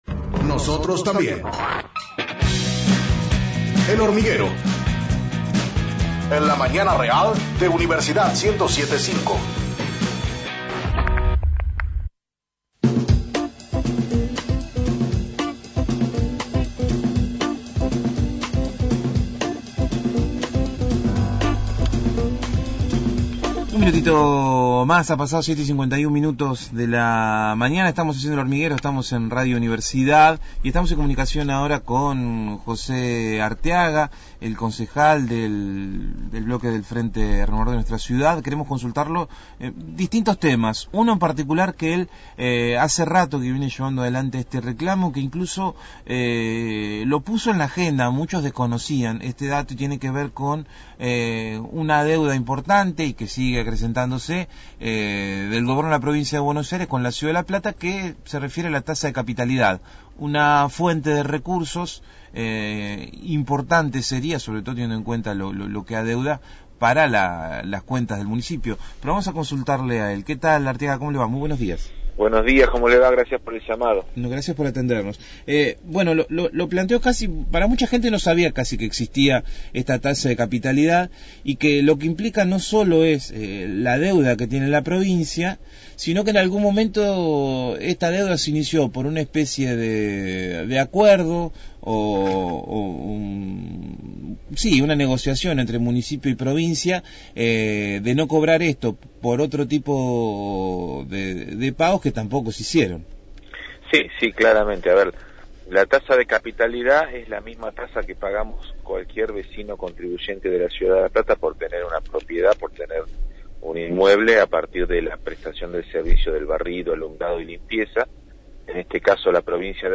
Entrevista al concejal José Arteaga (Frente Renovador) sobre la deuda que el gobierno de la provincia de Buenos Aires mantiene con la ciudad de La Plata. Programa: El Hormiguero.